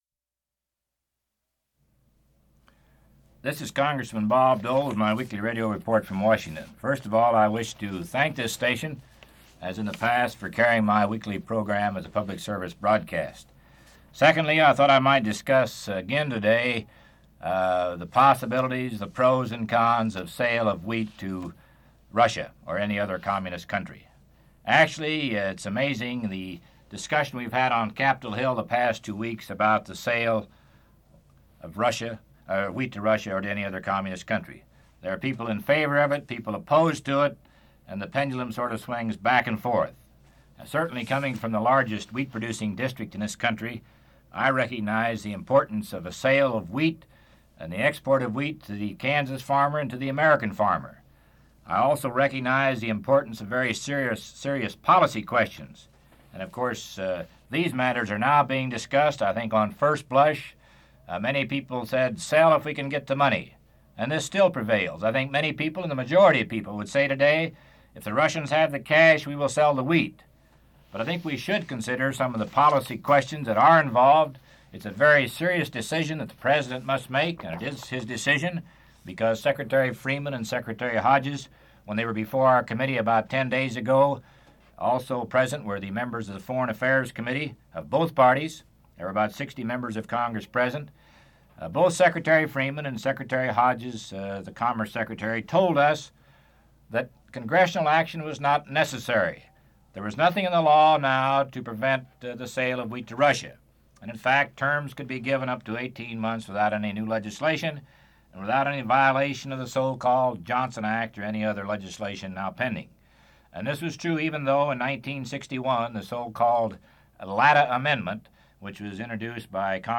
Part of Weekly Radio Report: Wheat Sales to the Soviet Union